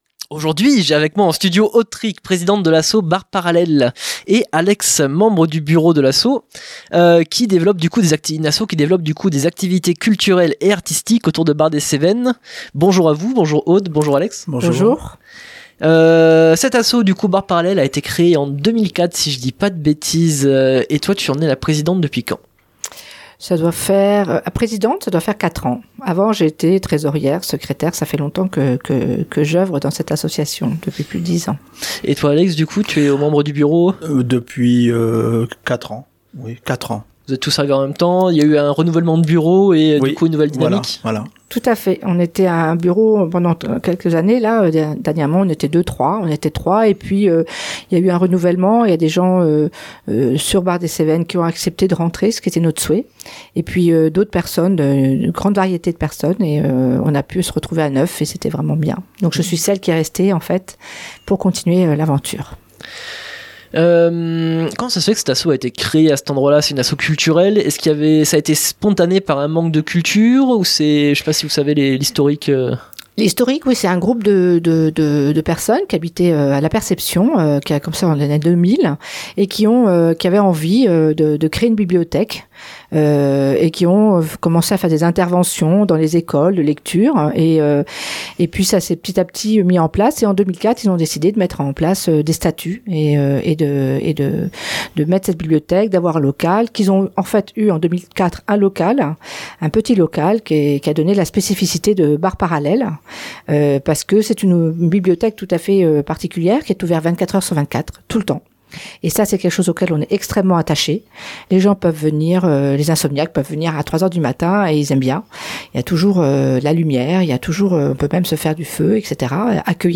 sont venus en studio ce vendredi 12 Avril 2024.